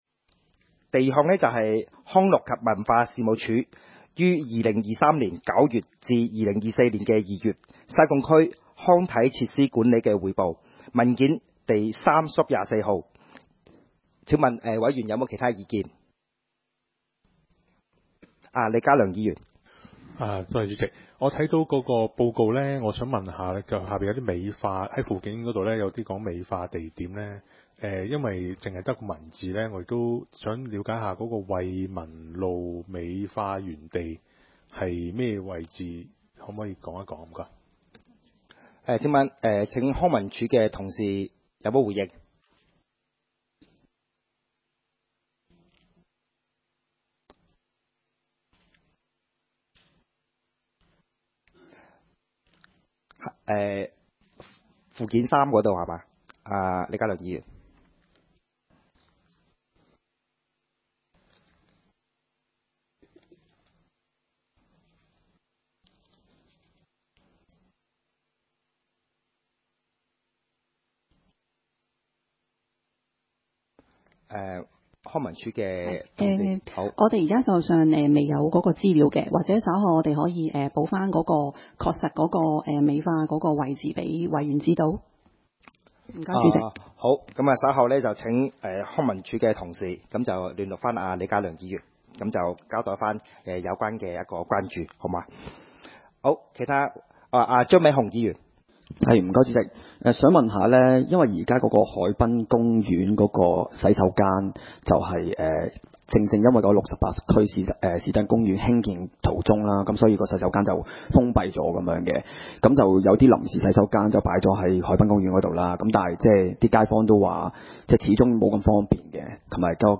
西貢區議會 - 區議會委員會會議的錄音記錄
會議的錄音記錄